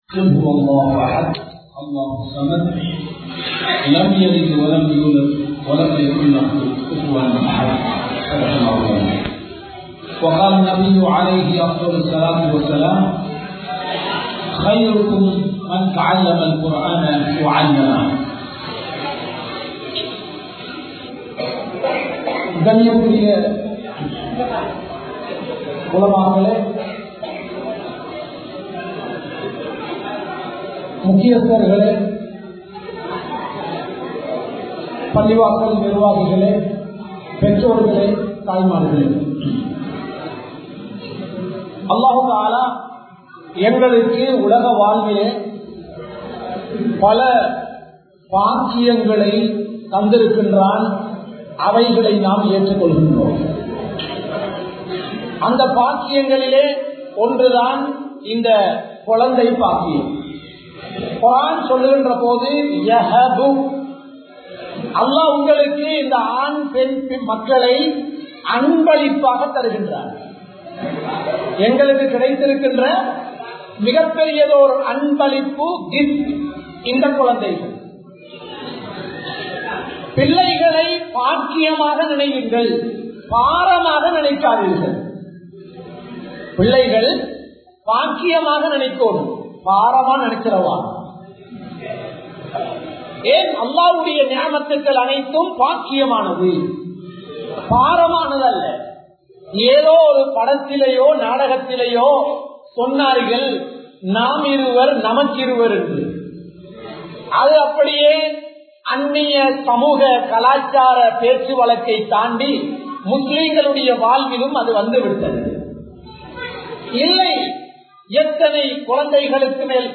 Thaayin Seyalum Kulanthayin Pirathipalippum(தாயின் செயலும் குழந்தையின் பிரதிபலிப்பும்) | Audio Bayans | All Ceylon Muslim Youth Community | Addalaichenai
Polannaruwa, Thambala Hilaal Jumua Masjith